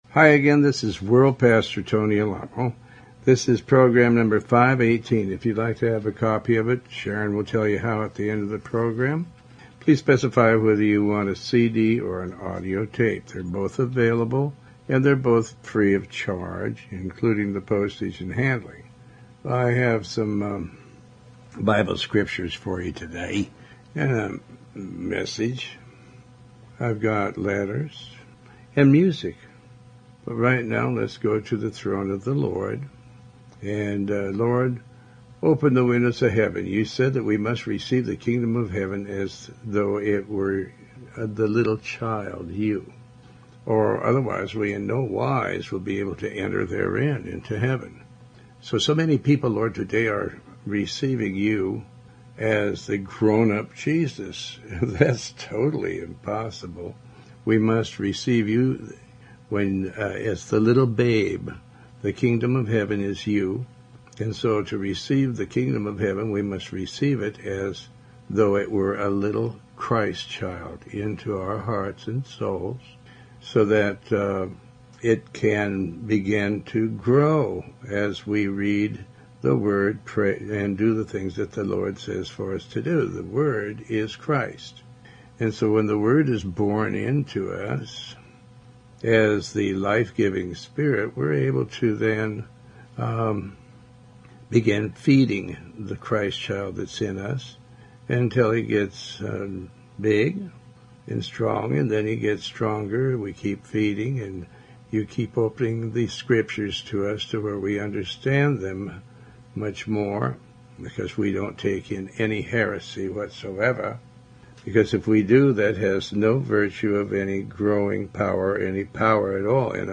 Talk Show Episode, Audio Podcast, Tony Alamo and Program 518 on , show guests , about pastor tony alamo,Tony Alamo Christian Ministries,Faith, categorized as Health & Lifestyle,History,Love & Relationships,Philosophy,Psychology,Christianity,Inspirational,Motivational,Society and Culture